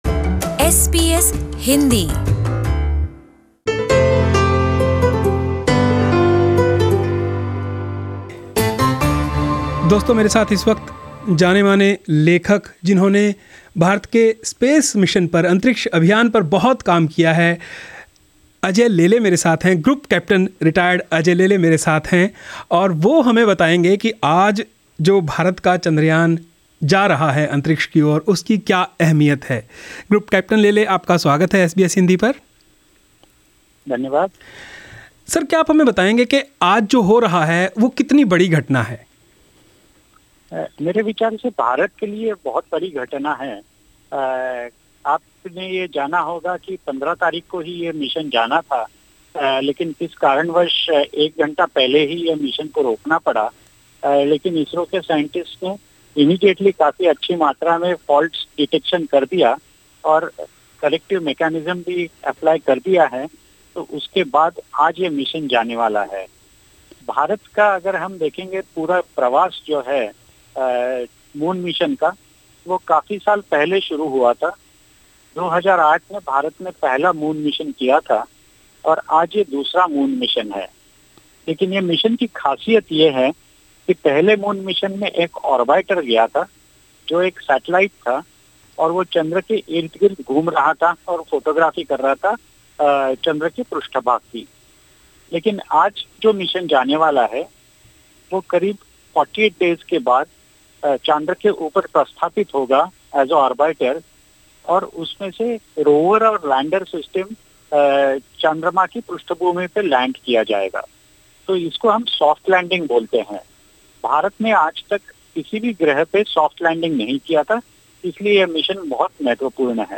Listen to this complete interview, here: